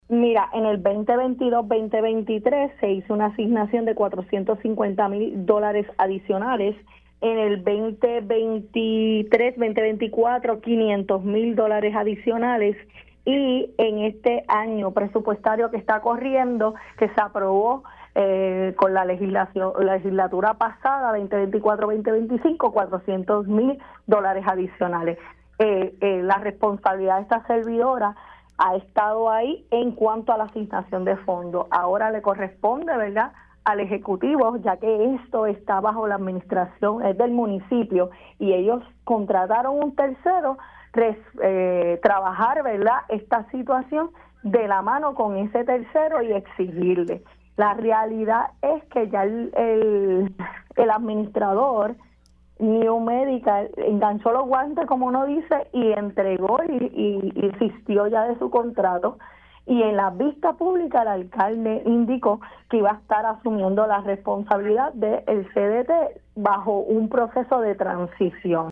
419-ESTRELLA-MARTINEZ-REP-PPD-LEGISLACION-SUYA-OTORGO-FONDOS-ADICIONALES-AL-CDT-SAN-TA-ISABEL.mp3